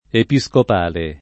[ epi S kop # le ]